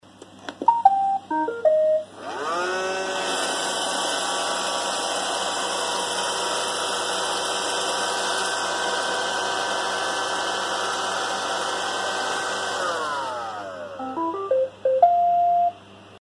Звуки робота-пылесоса
Механизм всасывания включается и выключается